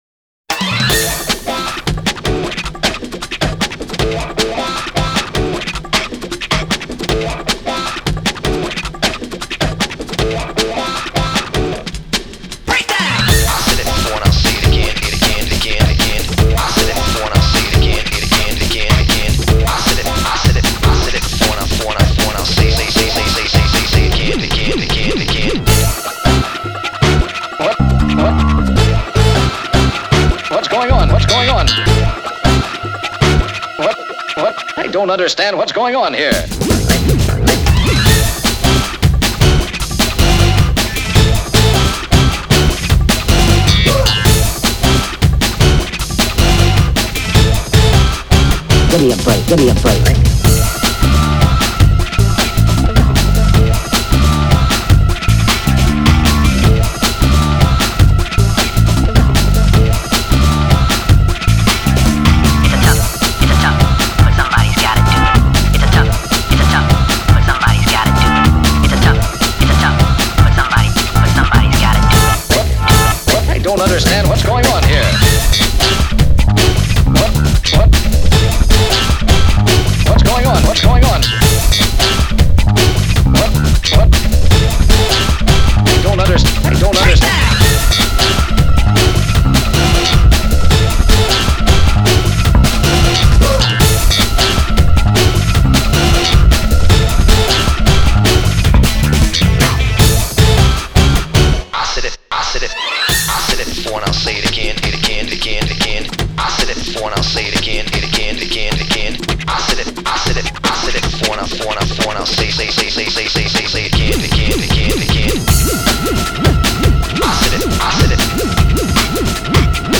BPM155
MP3 QualityMusic Cut